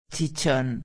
31/12/2011 Chichón Galo (cabeça) •\ [chi·chón] \• •\ Substantivo \• •\ Masculino \• Significado: Elevação produzida por contusão ou pancada, especialmente na cabeça.
chichon.mp3